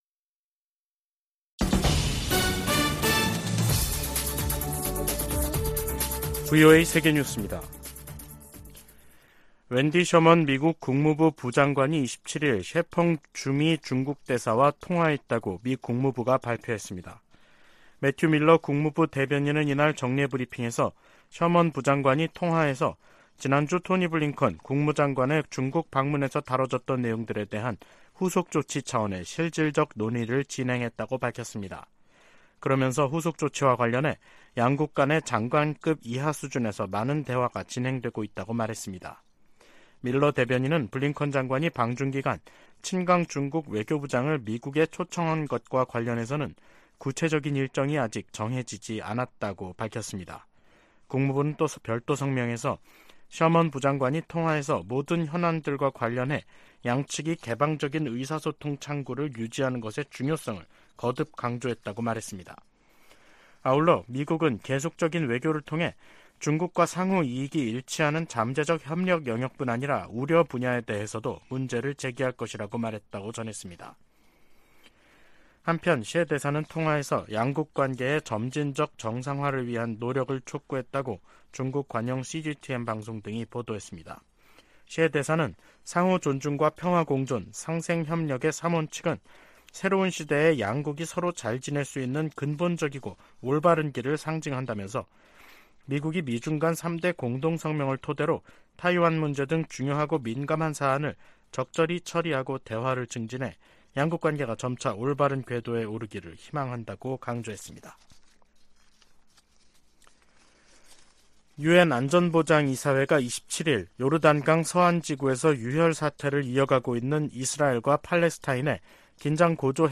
VOA 한국어 간판 뉴스 프로그램 '뉴스 투데이', 2023년 6월 28일 3부 방송입니다. 미 국무부는 미국과 한국의 군사활동 증가와 공동 핵계획 탓에 한반도 긴장이 고조되고 있다는 중국과 러시아의 주장을 일축했습니다. 미 국방부는 북한의 핵무력 강화 정책 주장과 관련해 동맹국과 역내 파트너들과의 협력을 강조했습니다. 미 하원 세출위원회가 공개한 2024회계연도 정부 예산안은 북한과 관련해 대북 방송과 인권 증진 활동에만 예산을 배정하고 있습니다.